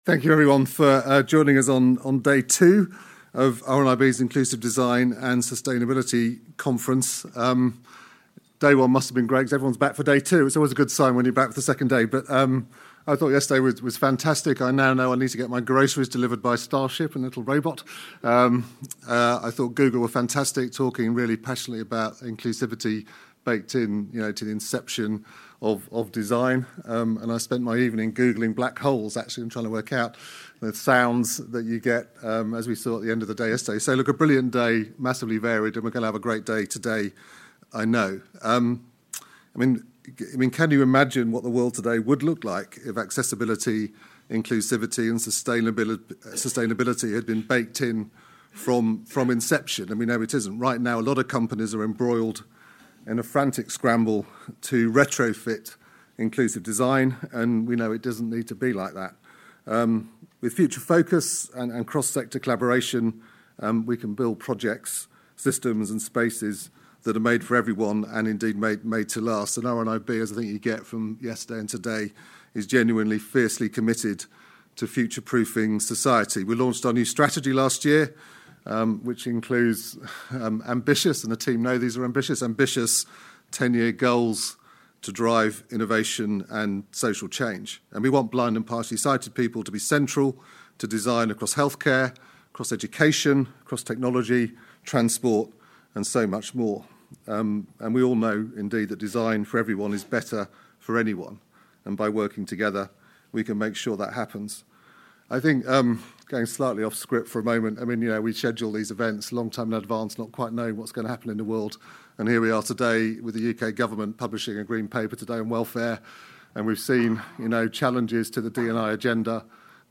Earlier this week, RNIB Scotland's Inclusive Design for Sustainability Conference returned to Glasgow for an exciting two-day event.